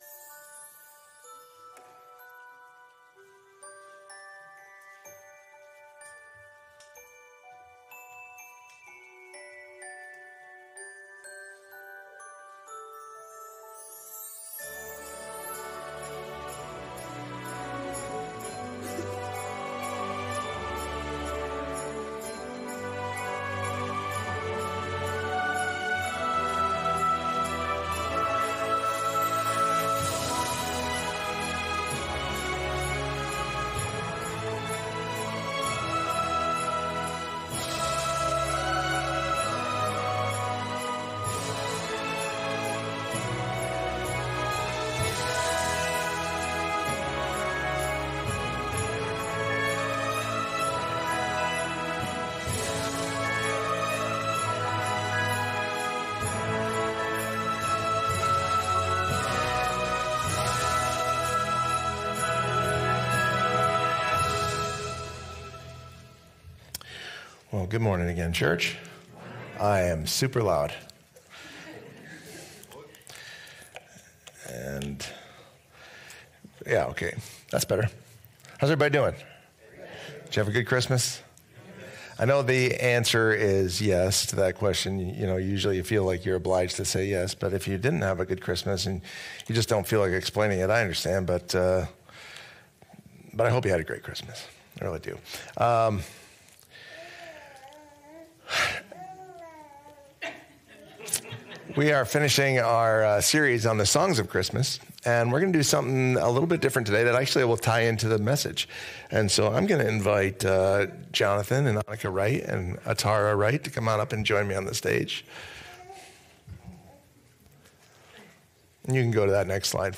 The Songs of Christmas Current Sermon